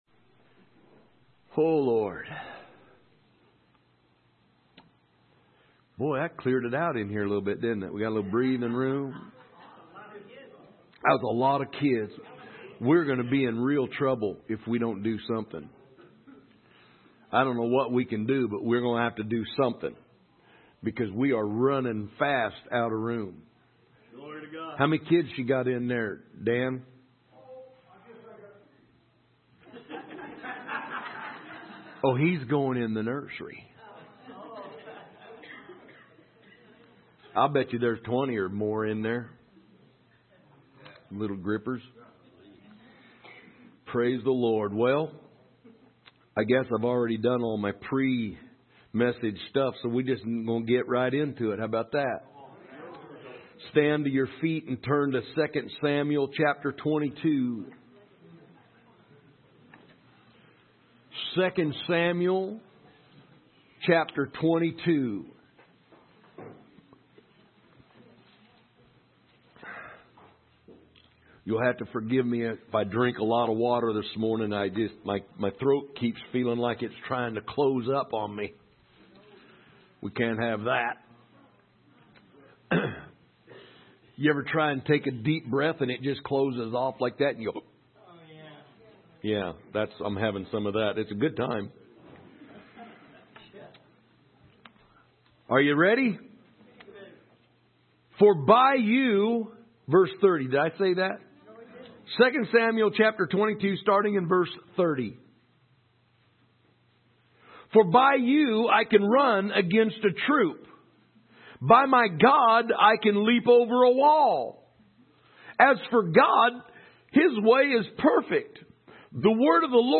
Category: Sermons , Teachings